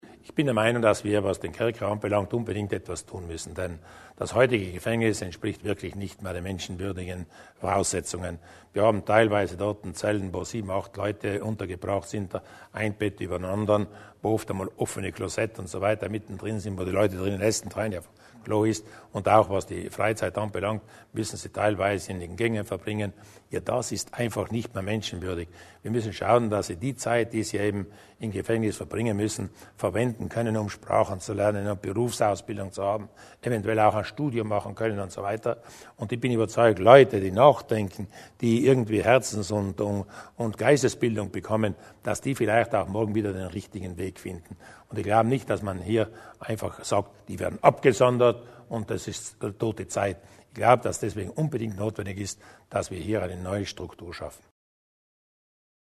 Landeshauptmann Durnwalder zum Projekt für das neue Gefängnis